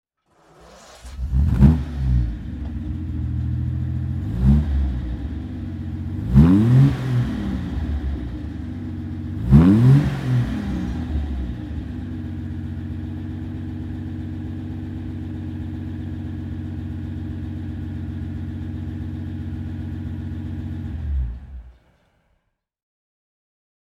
Lagonda V12 Saloon (1939) - Starten und Leerlauf